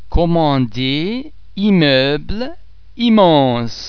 Please be mindful of the fact that all the French sounds are produced with greater facial, throat and other phonatory muscle tension than any English sound.
The French [m] and [mm] are normally pronounced a single [m] sound, as in the English words mother, famous, farm etc.